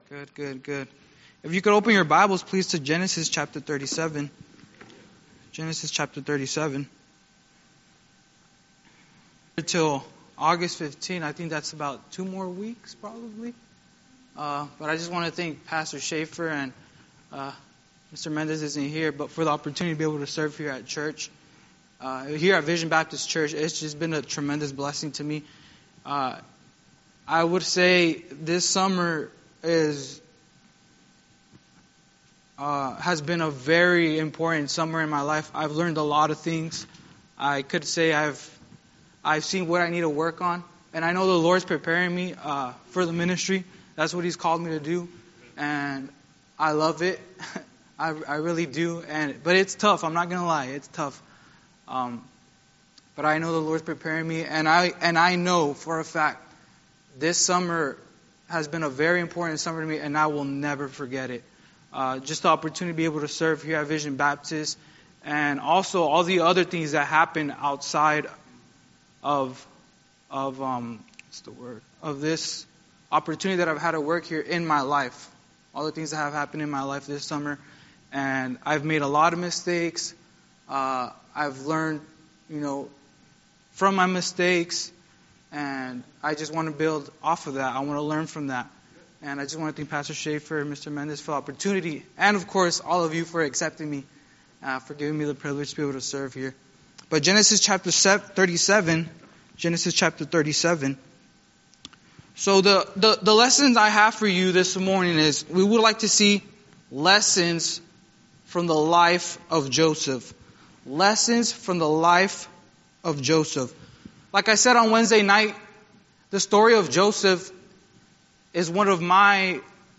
Sunday School Recordings
Series: Guest Speaker